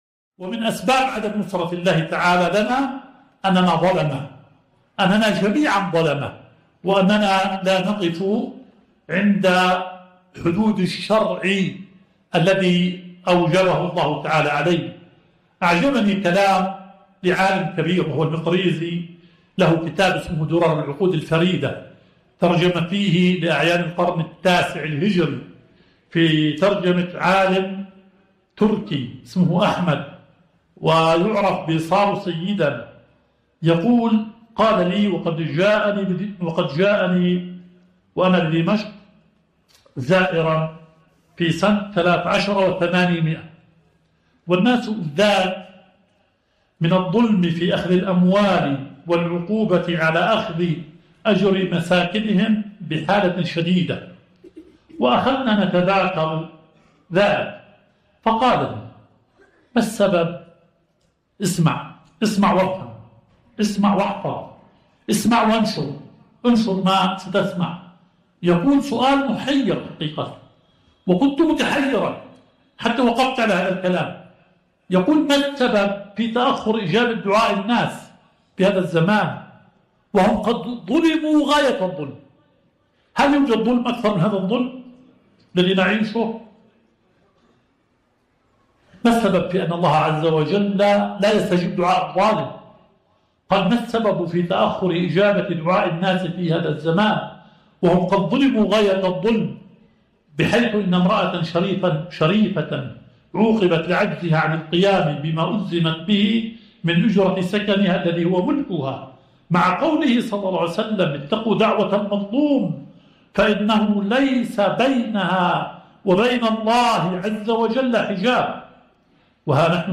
البث المباشر – لدرس شيخنا شرح صحیح مسلم – من كلمة مصلحة اليهود الكبرى اليوم وجود قناة “بن غوريون” محل قناة السويس.